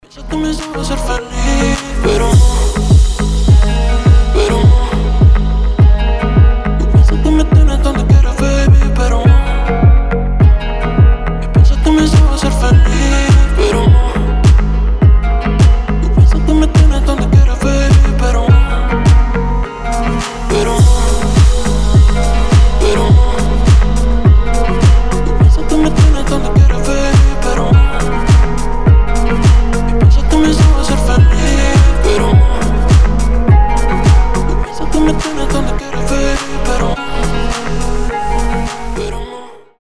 гитара
красивые
восточные мотивы
струнные
испанские